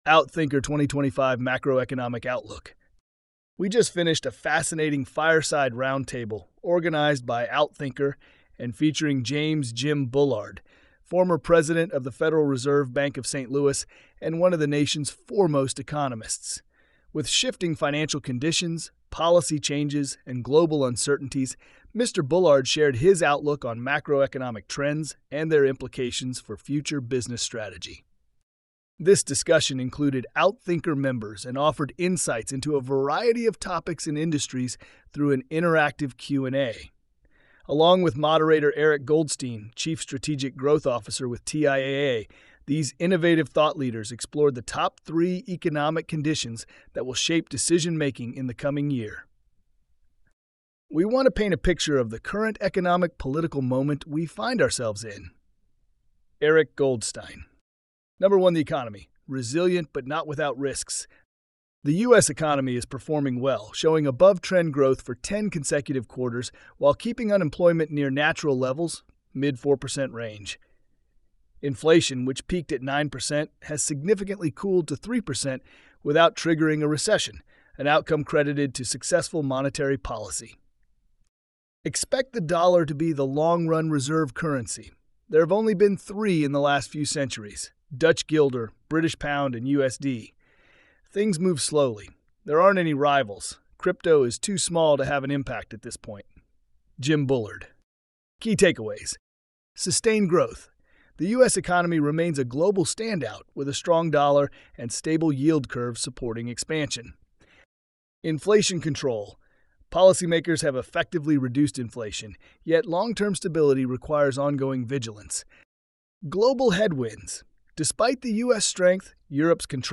This audio was recorded by AI.